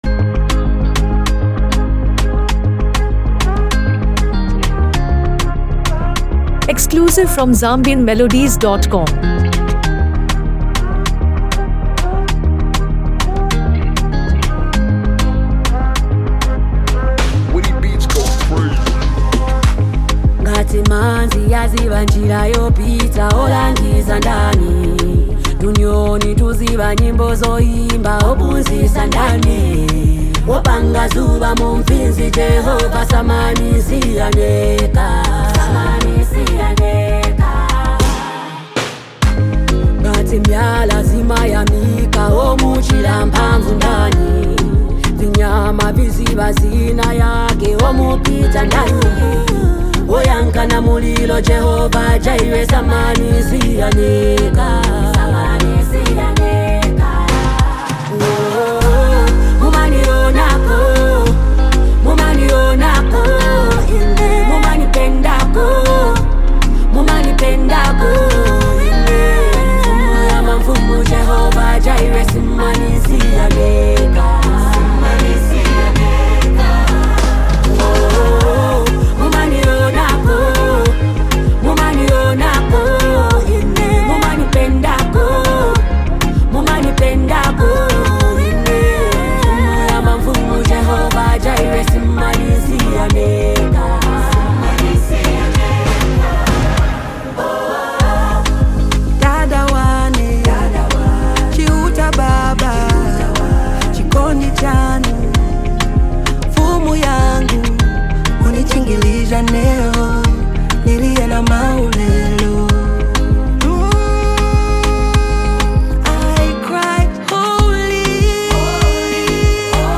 delivered by two of Zambia’s most powerful female voices